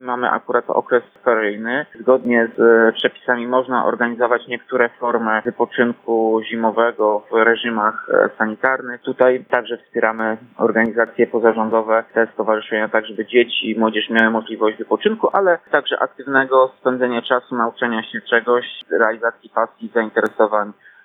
Mówi Tomasz Andrukiewicz, prezydent Ełku: